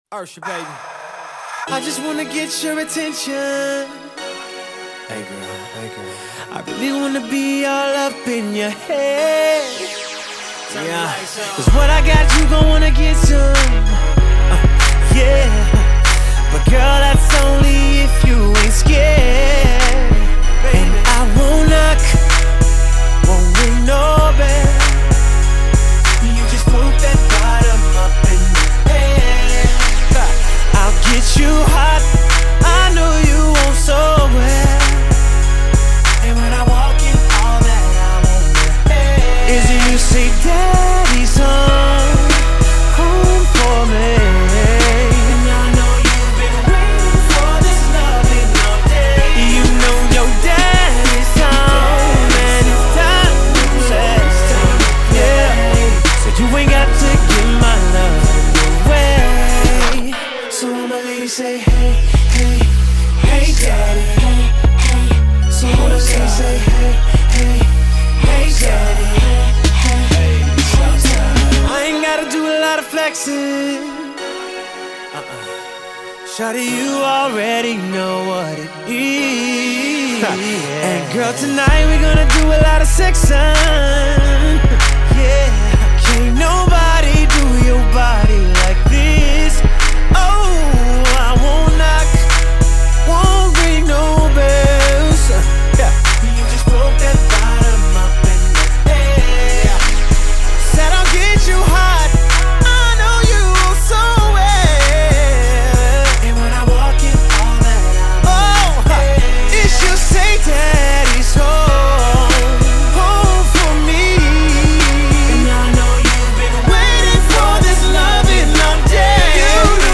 R&b rap hip-hop